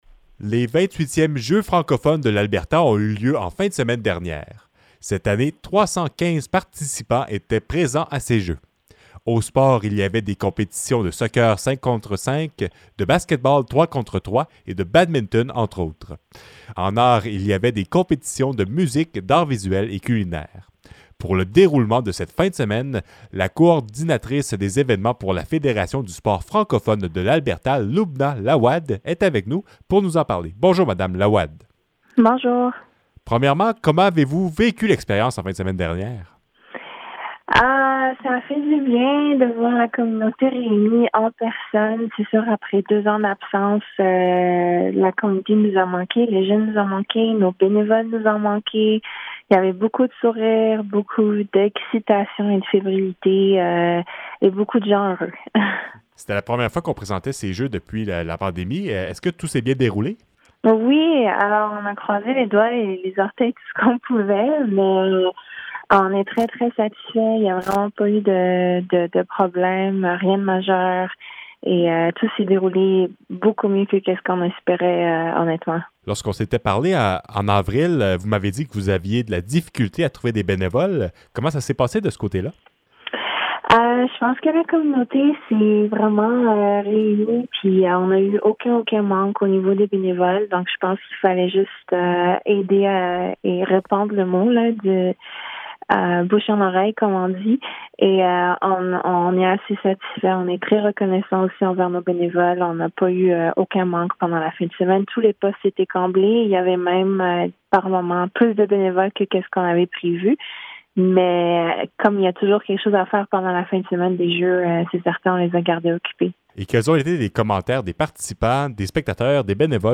Entrevue-Jeux-Francophones-Integrale.mp3